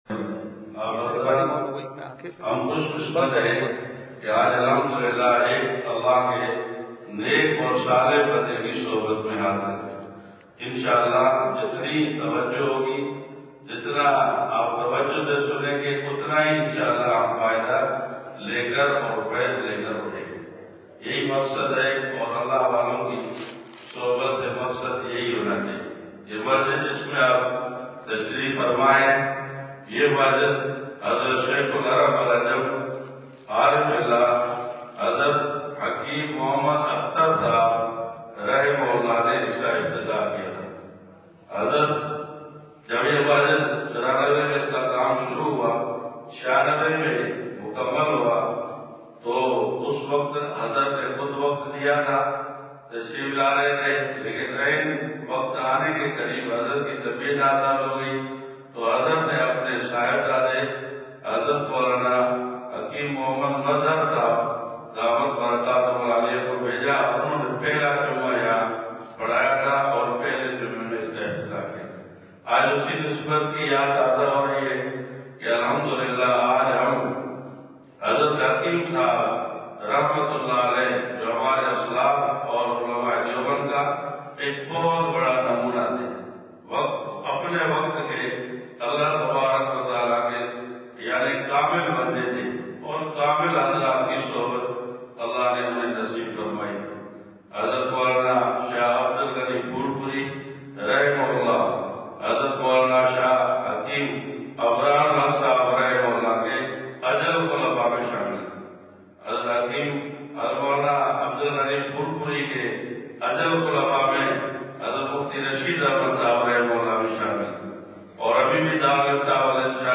مدرسہ دارالعلوم نواب شاہ سندھ (بعد مغرب بیان)